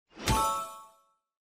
star_sound1.mp3